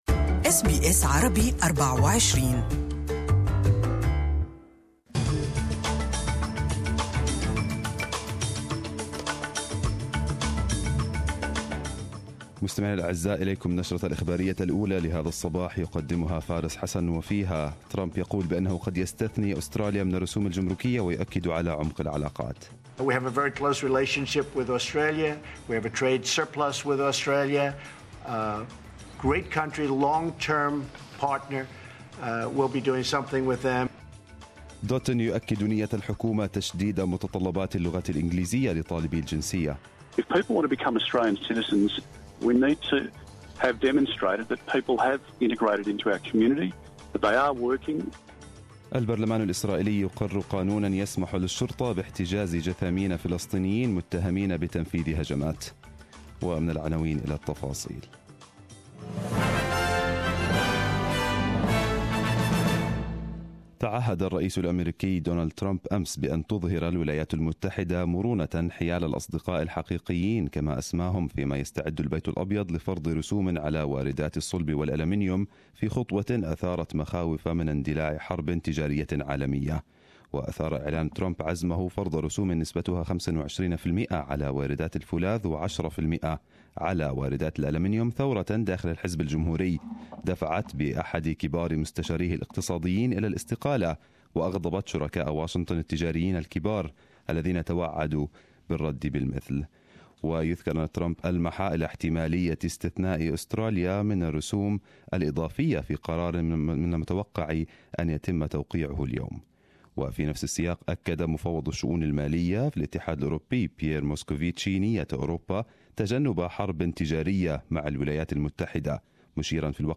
Arabic News Bulletin 09/03/2018